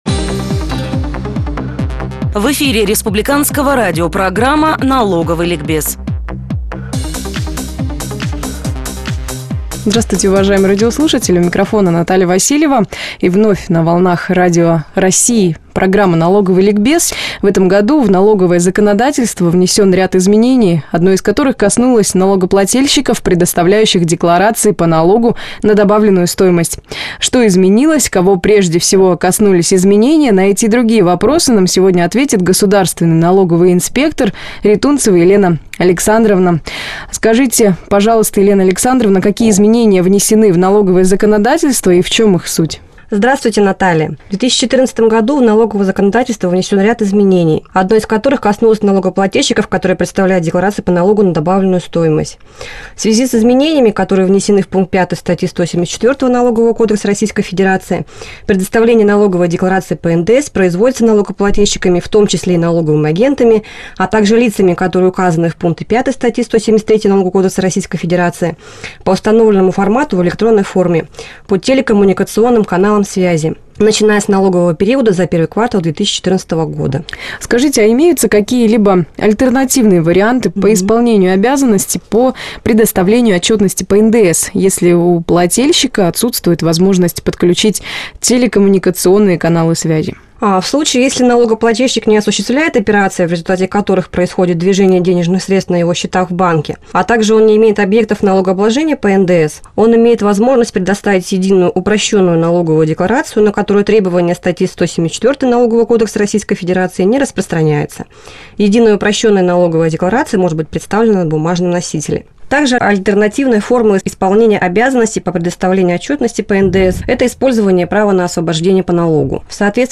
Об обязанности представлять налоговые декларации по НДС в электронной форме в эфире «Радио России»